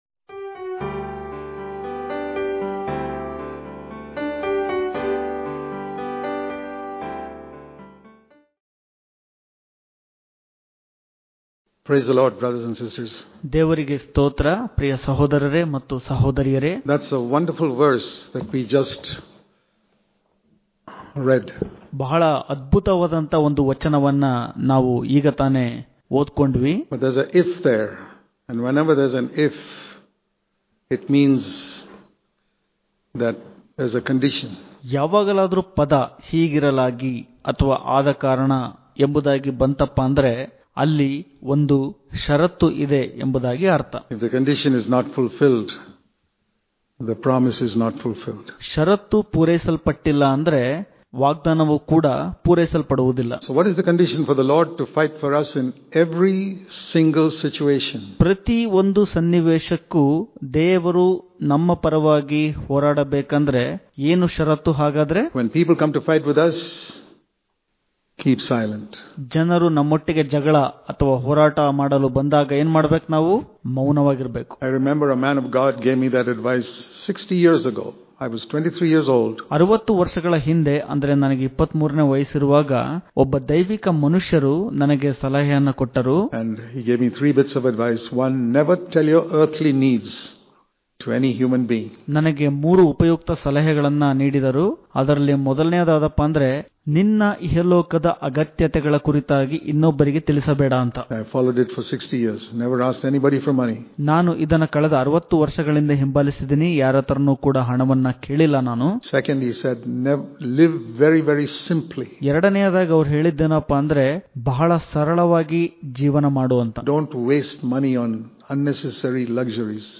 July 5 | Kannada Daily Devotion | The Lord Will Fight For You If You Keep Silent Daily Devotions